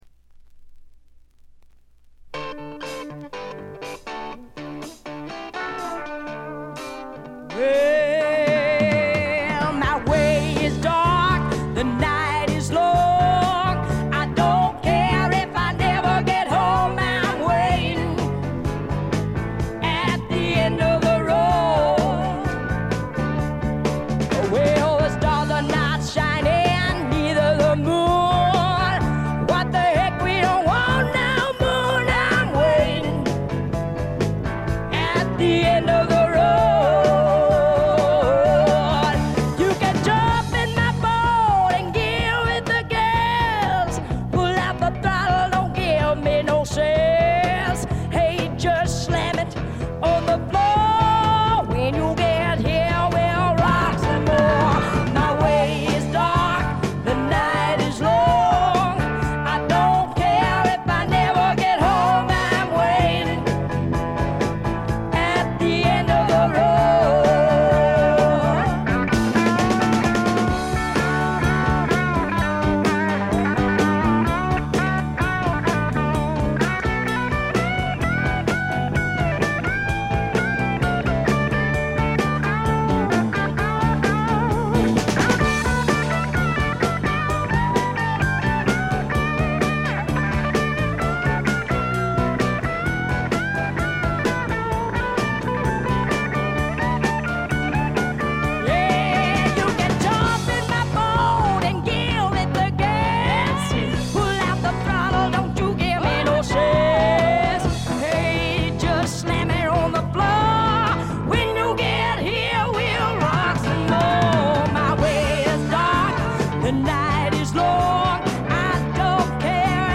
ほとんどノイズ感無し。
フィメール・スワンプの大名盤です！
超重量級スワンプ名作。
試聴曲は現品からの取り込み音源です。
Vocals